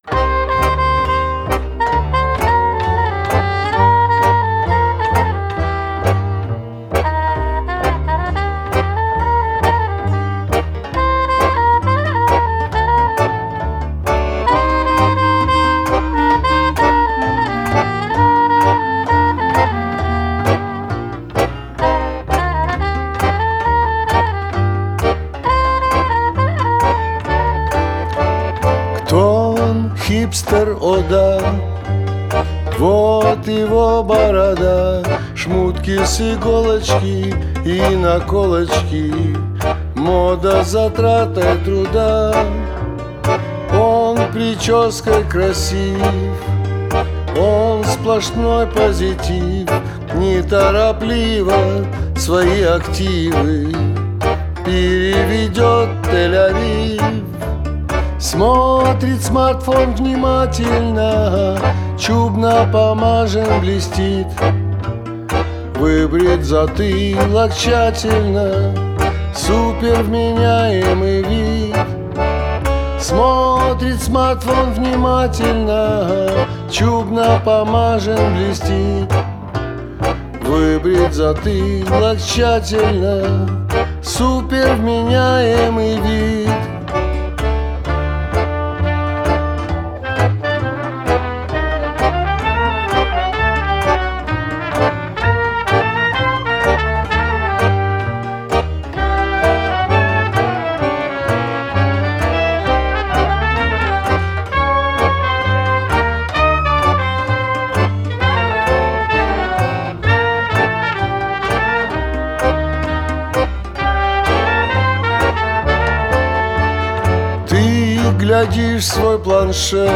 Genre: Klezmer, Balkan, Gypsy Jazz, Worldbeat